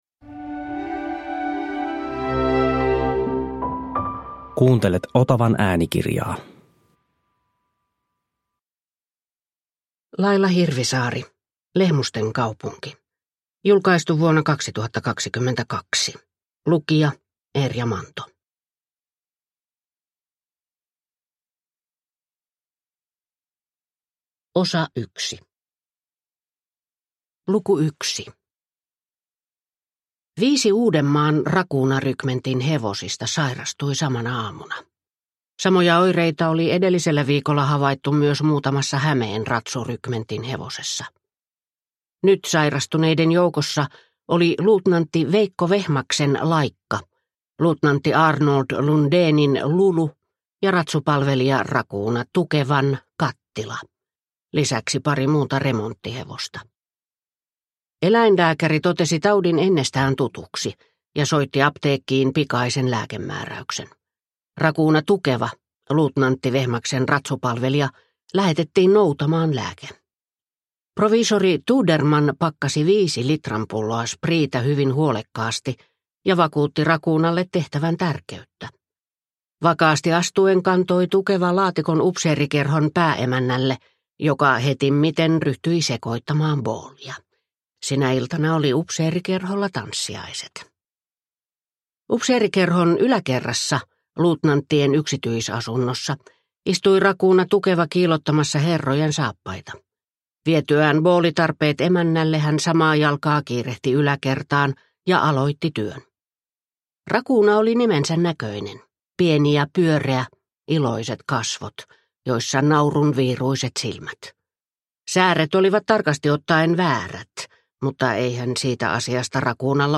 Lehmusten kaupunki – Ljudbok – Laddas ner